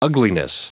Prononciation du mot ugliness en anglais (fichier audio)
Prononciation du mot : ugliness